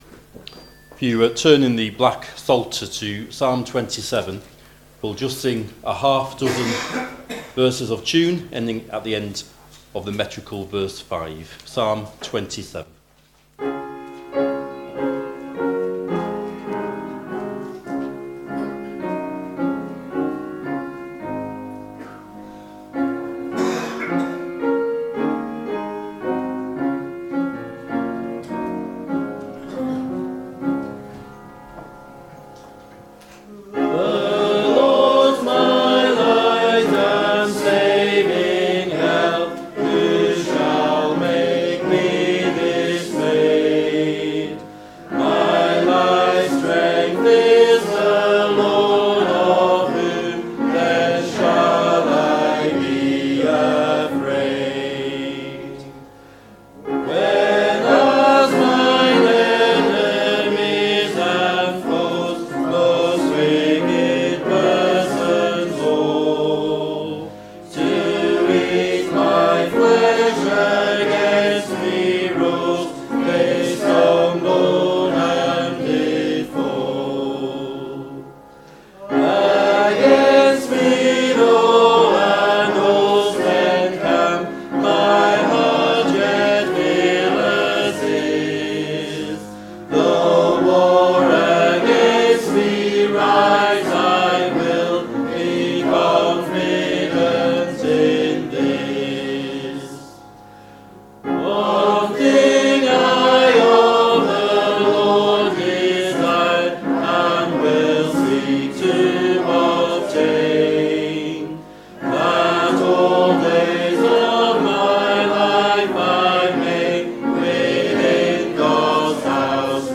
Below is audio of the full service.